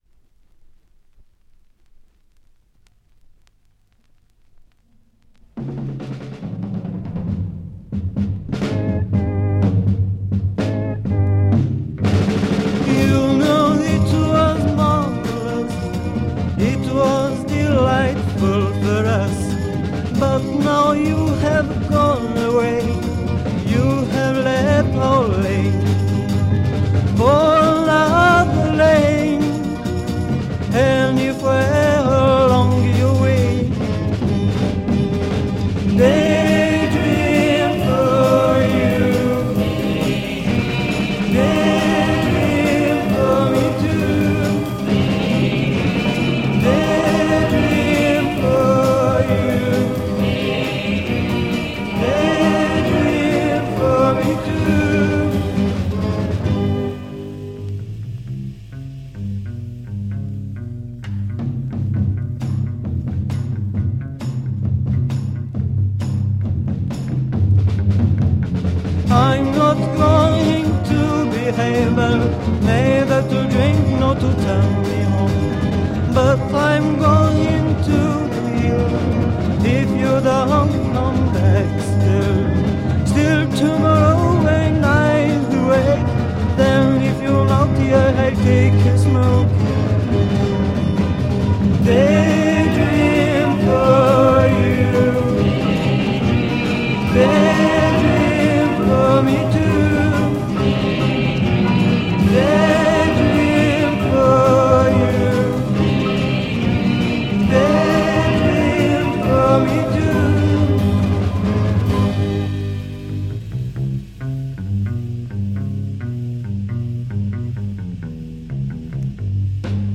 French Swiss Private Freakbeat Psych low-fi